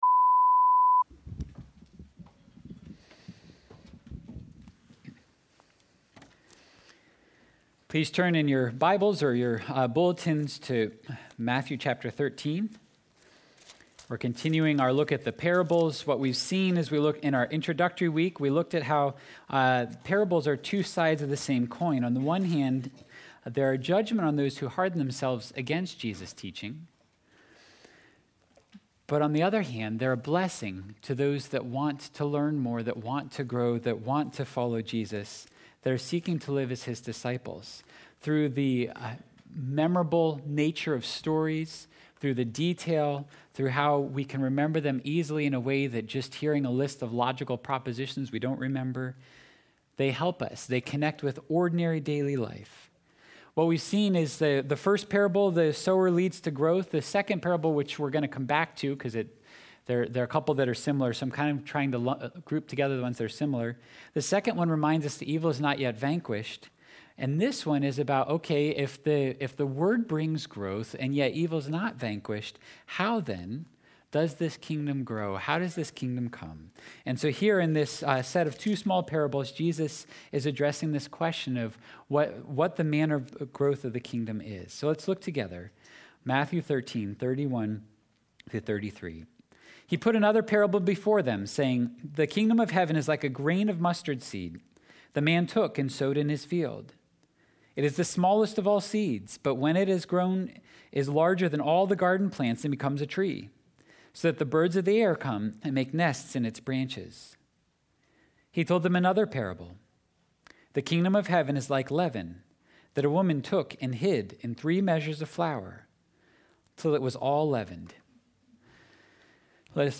Sunday Worship – January 23 of 2022 – Surprising Growth
1.23.22-sermon-audio.mp3